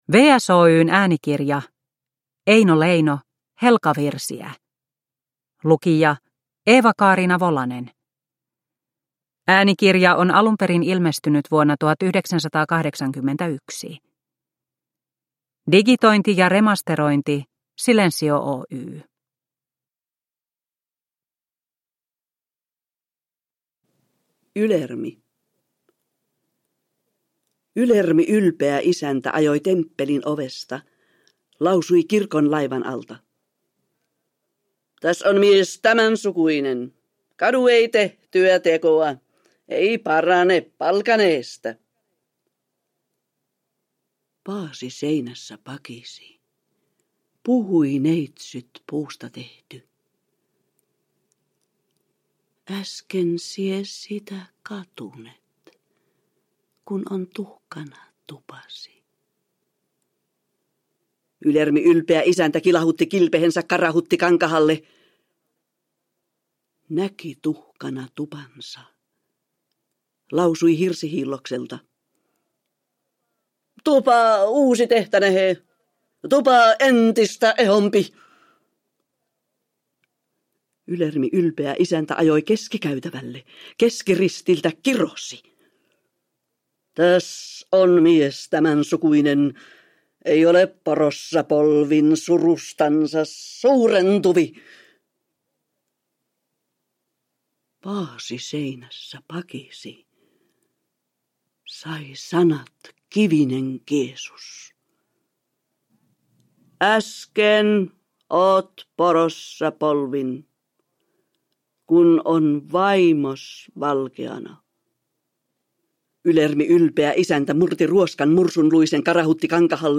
Valittuja runoja kansallisrunoilijan pääteoksesta Eeva-Kaarina Volasen tulkitsemina.
Runot lausuu legendaarinen näyttelijä Eeva Kaarina Volanen (1921–1999).
Uppläsare: Eeva-Kaarina Volanen